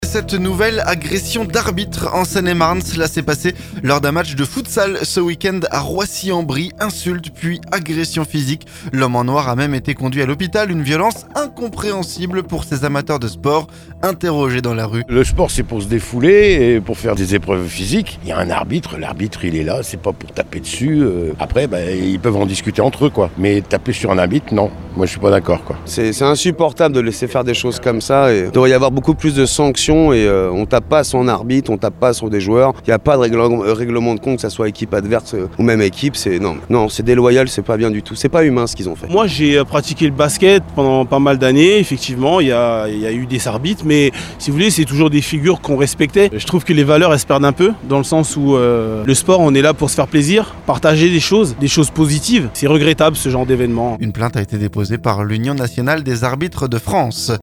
Une violence incompréhensible pour ces amateurs de sports interrogés dans la rue…